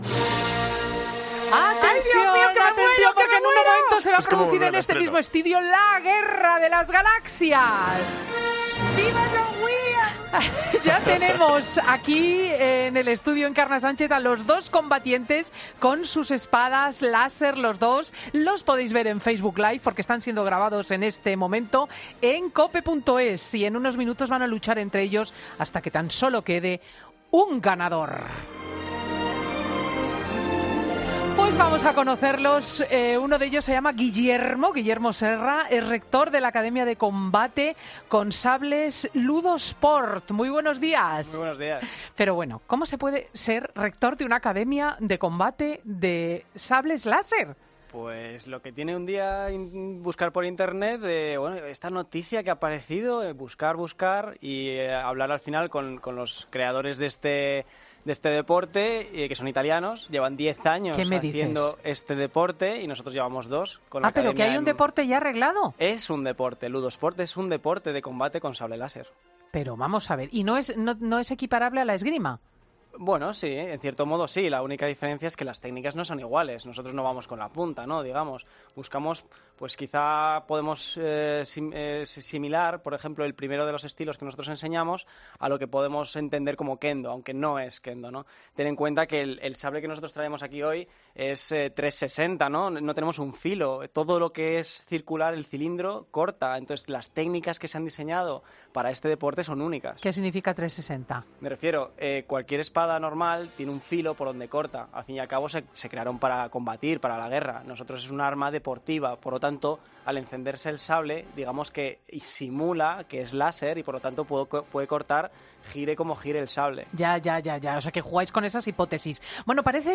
Paco González narra la primera lucha de espadas láser a lo Star Wars